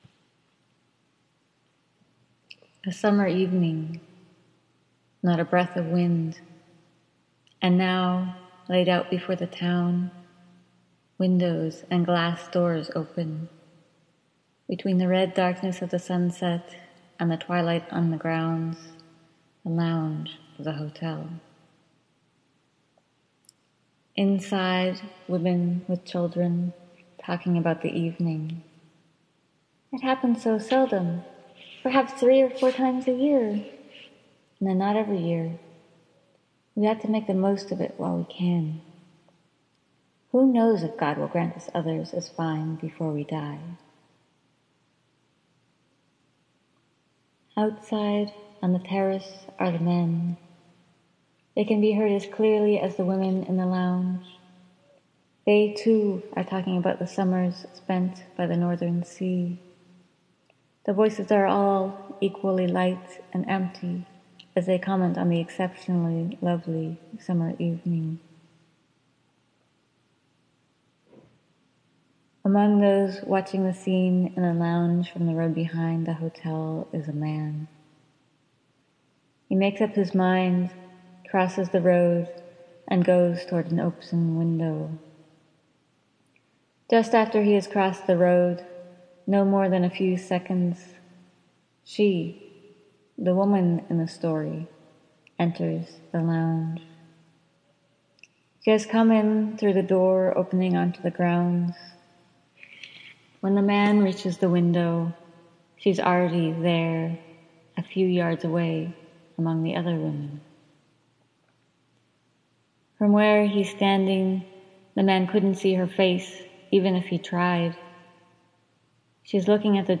duras-expressive-patched-voices-Output.m4a